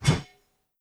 sword_woosh_3.wav